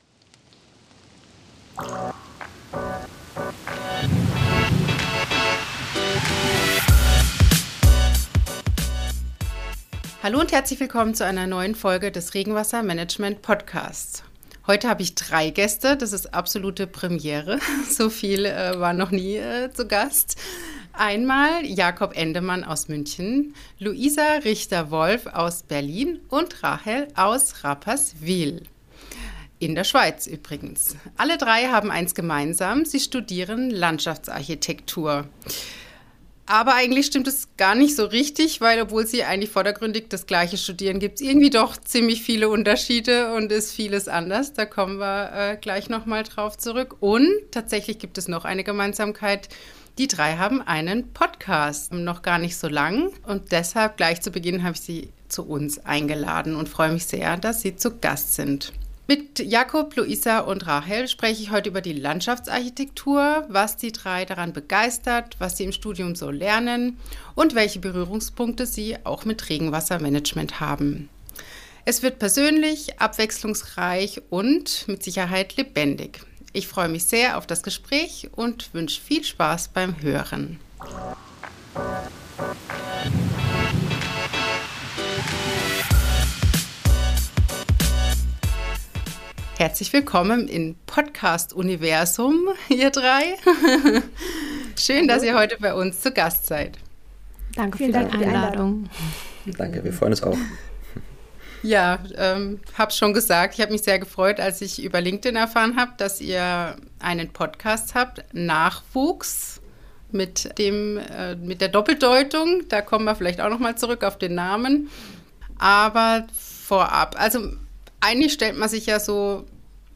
Nachwuchs Landschaftsarchitektur - Vielfalt, Verantwortung & Visionen - Ein Gespräch mit Studierenden ~ Regenwassermanagement Podcast für die Baupraxis Podcast